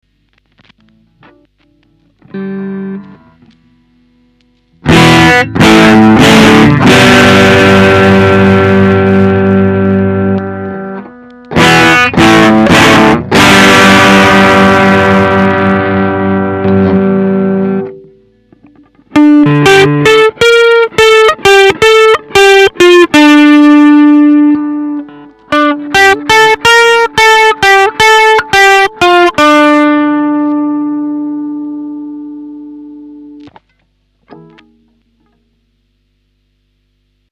G&L ストラト